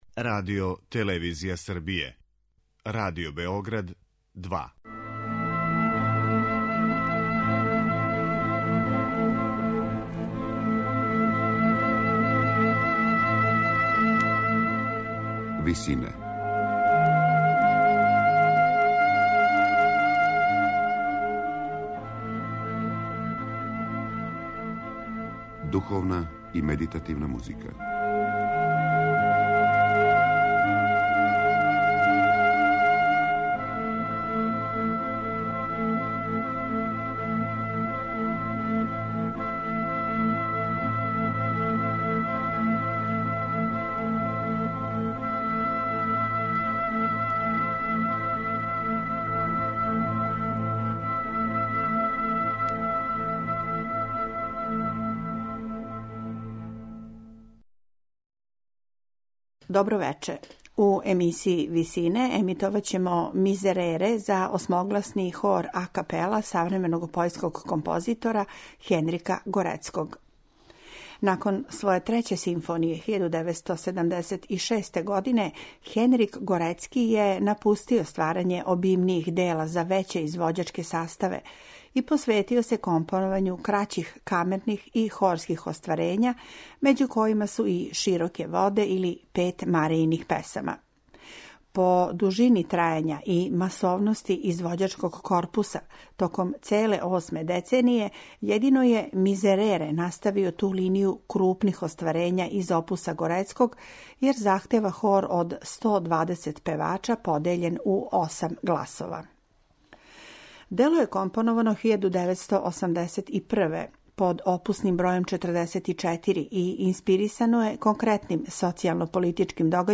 за осмогласни хор